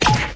chair_impact.wav